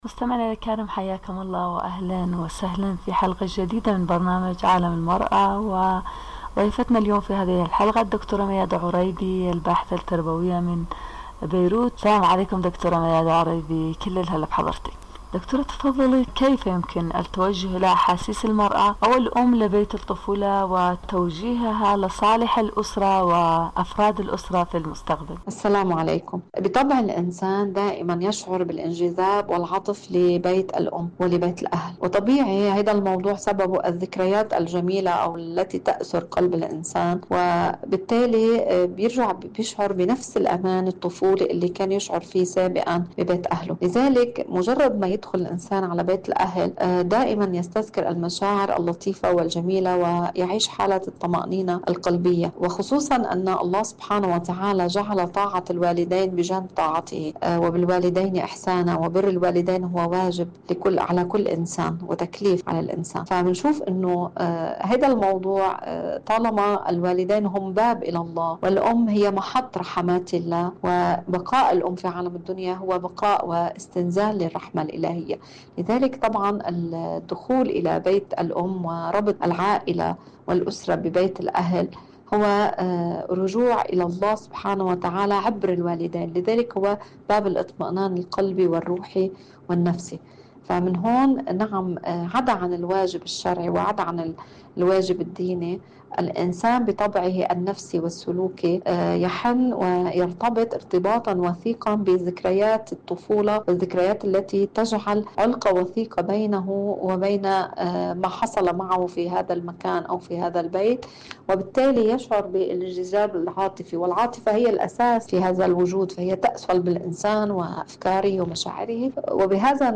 إذاعة طهران- عالم المرأة: مقابلة إذاعية